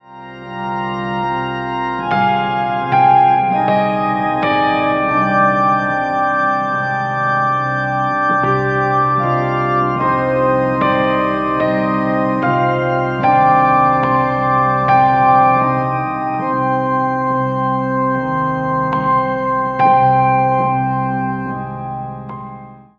Electronic Organ
Kamanche